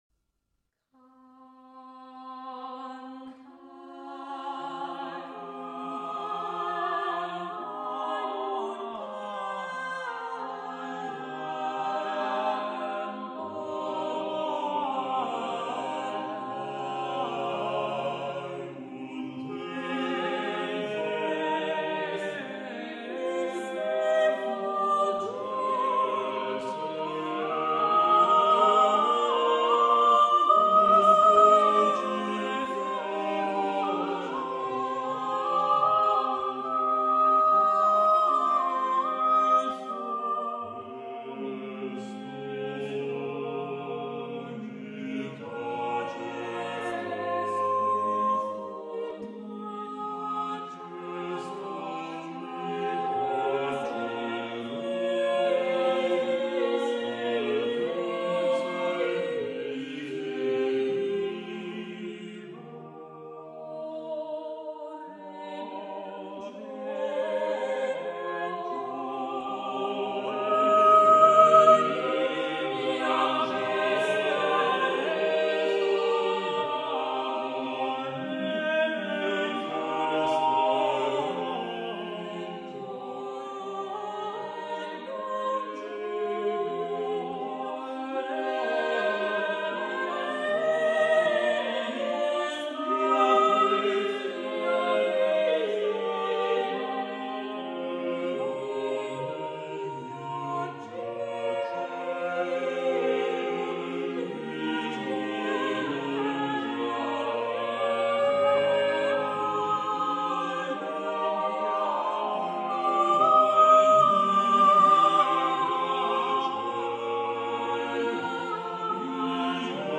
Madrigale a 5 voci "Cantai un tempo" su testo del Bembo. Complesso Barocco diretto da Alan Curtis "Cantai un tempo", Claudio Monteverdi (II Libro dei Madrigali, 1590) su testo di P. Bembo